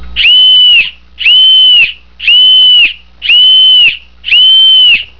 Call the Boatswain's Mates...The boatswains gang to report, or Call Mates.
The call is two short, shrill peeps in the clinched position, repeated once.